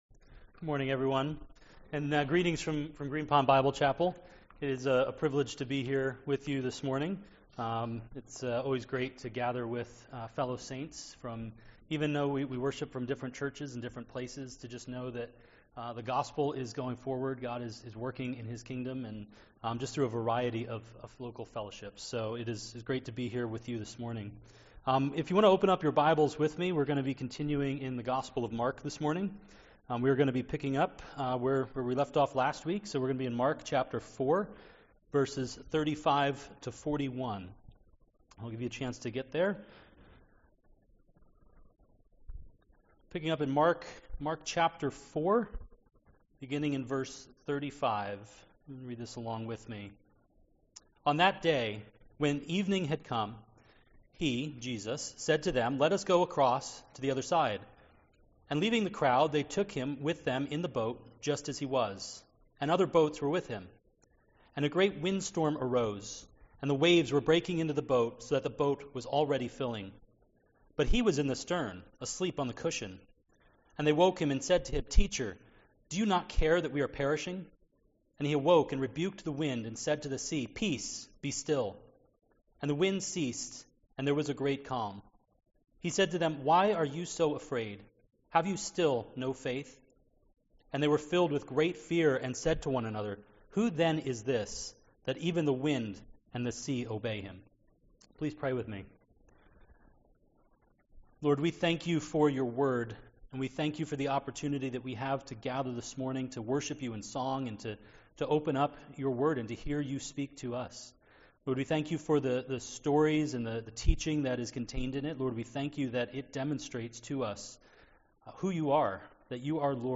Sermons Archive - New Beginnings Bible Church